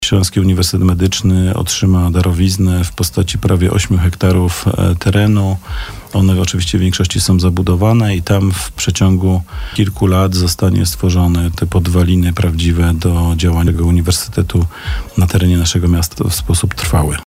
Ten temat jakiś czas temu poruszaliśmy w rozmowie w zastępcą prezydenta miasta, Przemysławem Kamińskim.